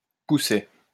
Poussay (French pronunciation: [pusɛ]